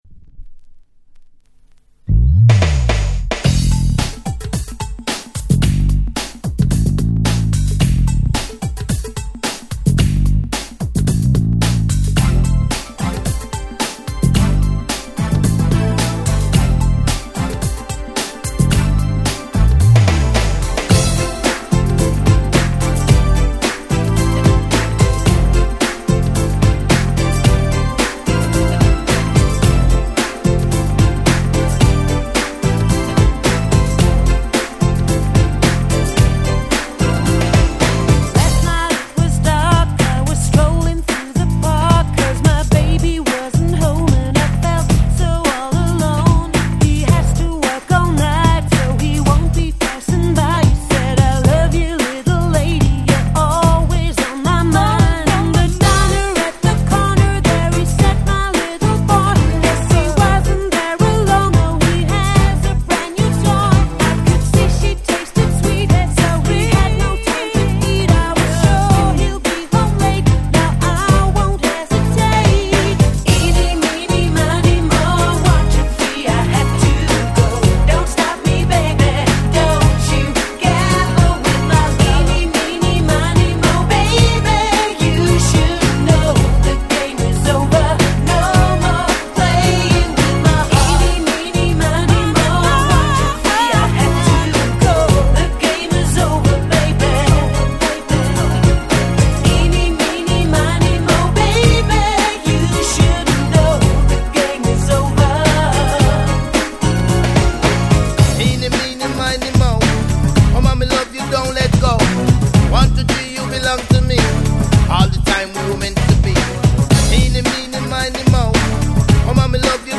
97' キャッチーR&B最高峰！！
謎のSexy系おねーちゃん二人組。
「イニミニマニモー」とキャッチーで爽快なサビがたまりません！！